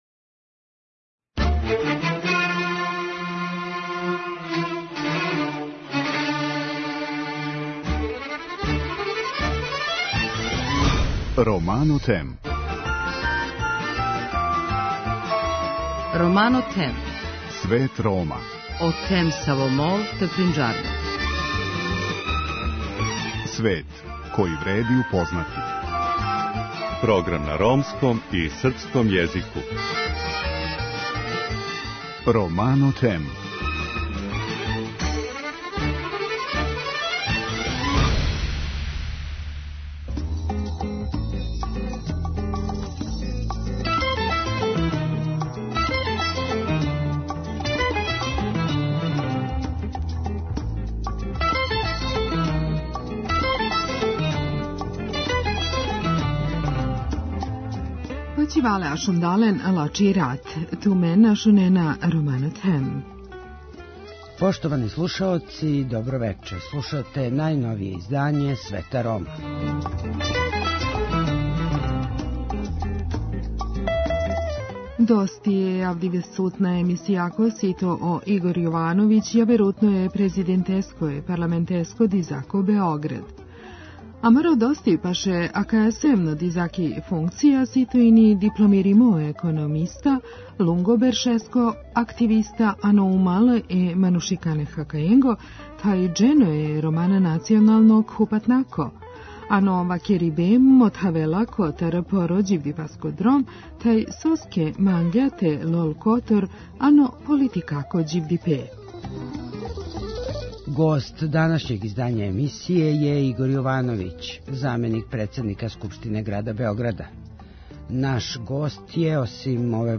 Гост данашњег издања емисије је Игор Јовановић, заменик председника Скупштине града Београда.